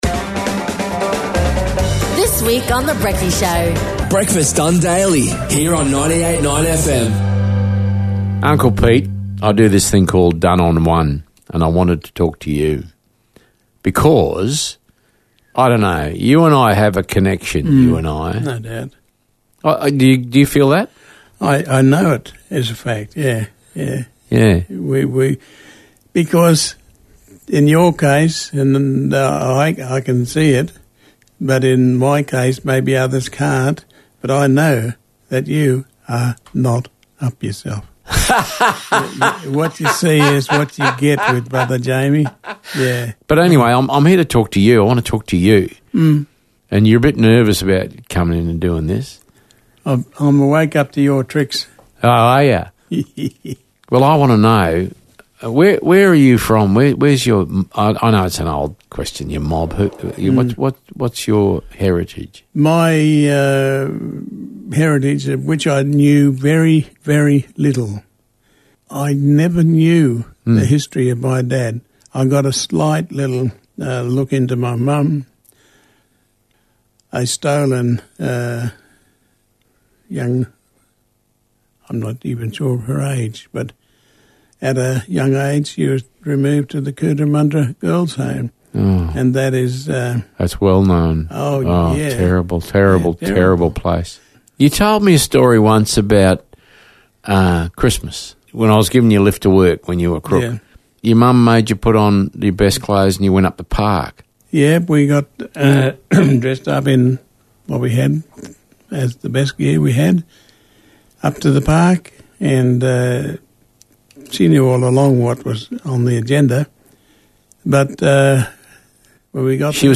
A very personal and intimate Interview